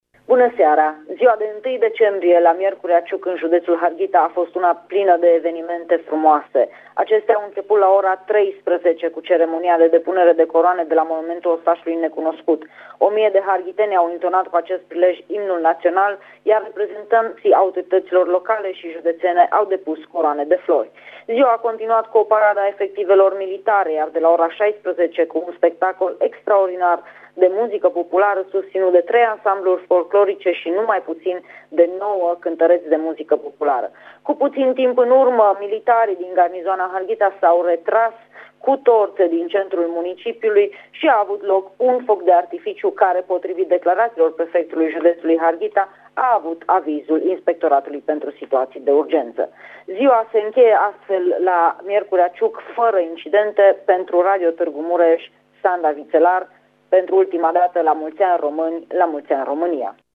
a fost prezentă la evenimentele organizate la Miercurea Ciuc și ne oferă amănunte: